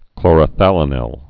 (klôrə-thălə-nĭl)